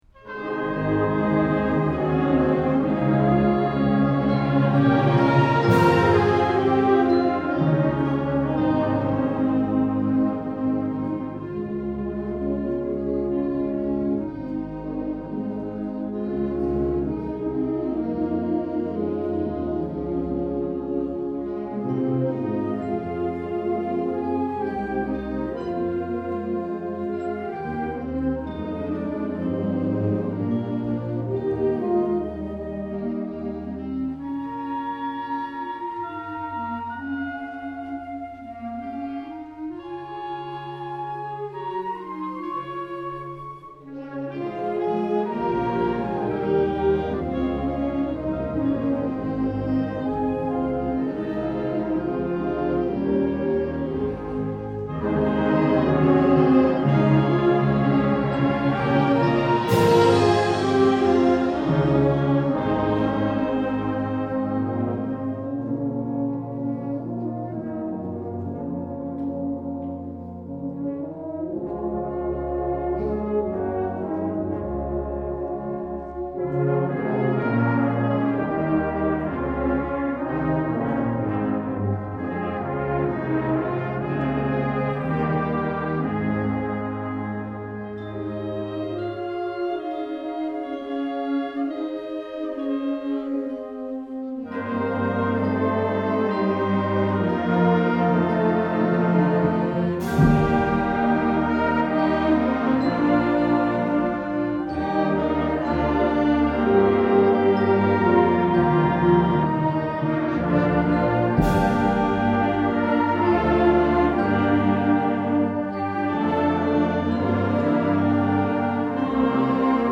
2008 Summer Concert
June 22, 2008 - San Marcos High School